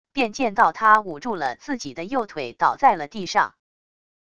便见到他捂住了自己的右腿倒在了地上wav音频生成系统WAV Audio Player